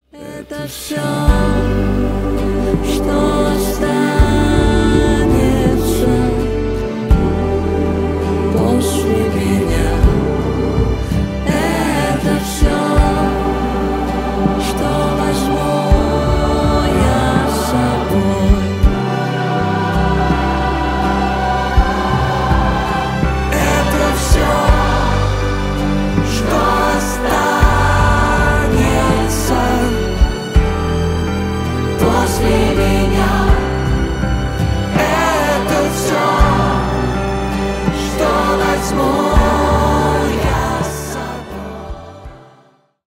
• Качество: 320 kbps, Stereo
Поп Музыка
кавер
спокойные